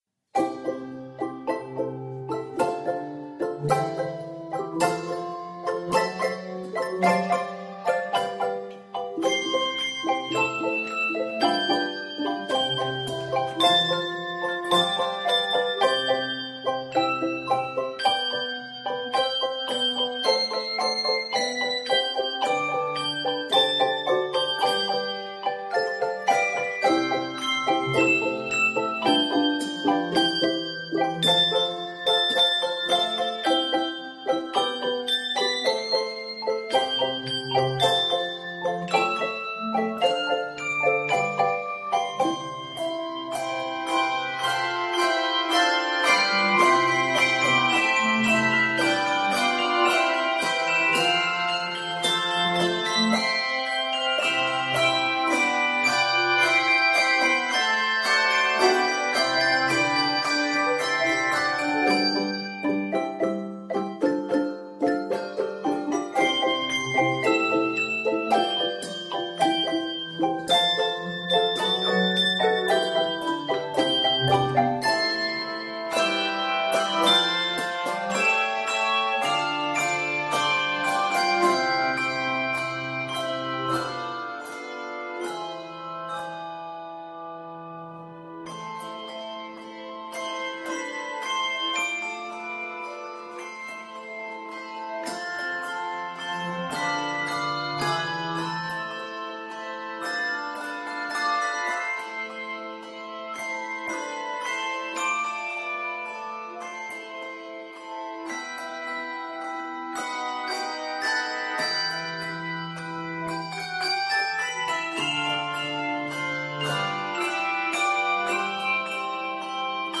it is scored in C Major and a minor.